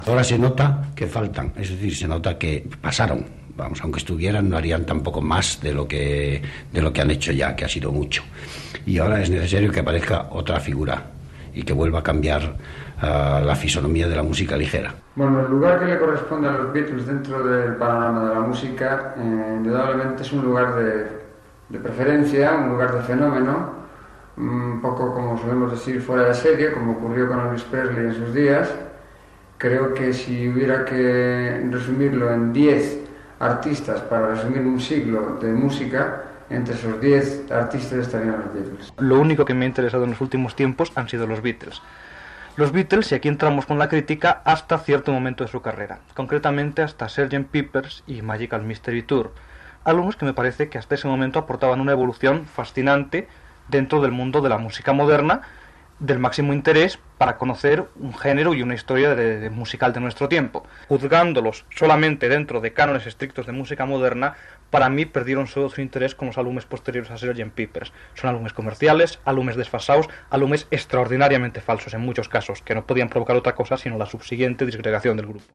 Opinions del director d'orquestra Odón Alonso, el compositor Fernando Arbex i el crític musical Fernando Pérez de Arteaga sobre The Beatles
Informatiu